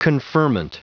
Prononciation du mot conferment en anglais (fichier audio)
Prononciation du mot : conferment